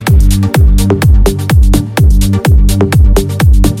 ритмичные
Electronic
EDM
без слов
клубняк